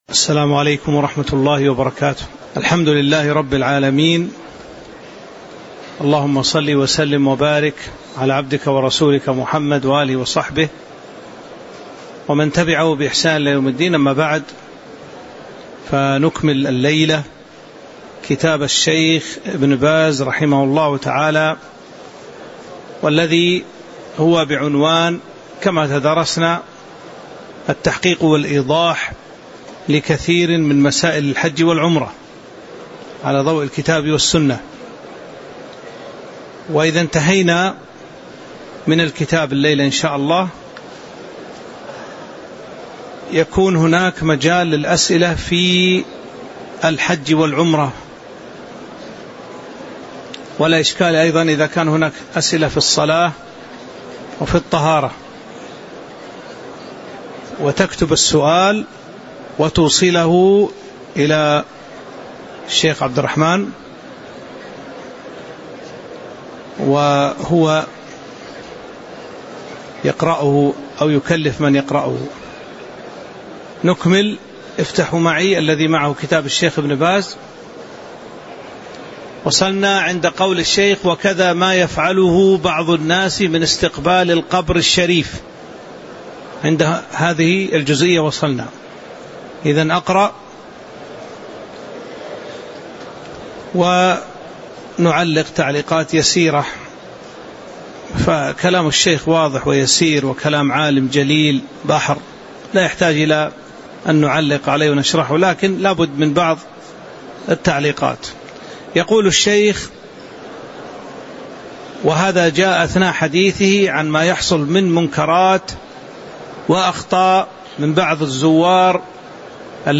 تاريخ النشر ١ ذو الحجة ١٤٤٦ هـ المكان: المسجد النبوي الشيخ